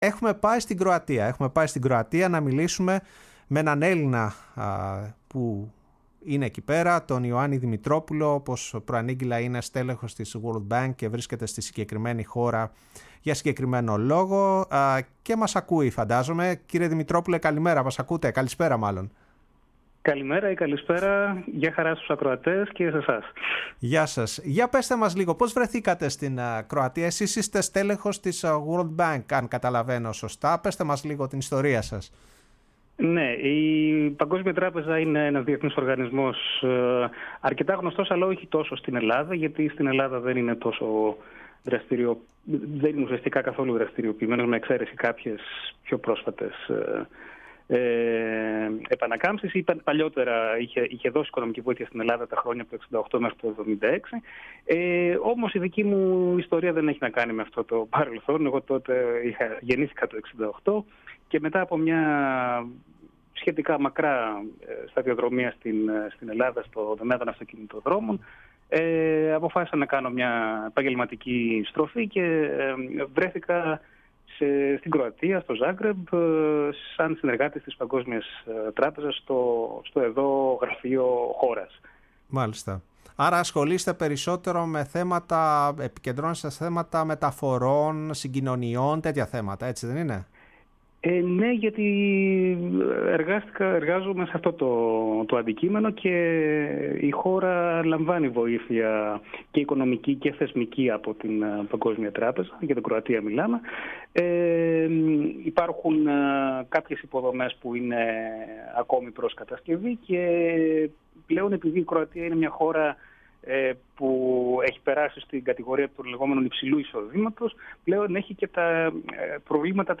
Η Κροατία ήταν το θέμα της εκπομπής «Η Παγκόσμια Φωνή μας» στο ραδιόφωνο της Φωνής της Ελλάδας.